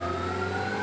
Accelerate.wav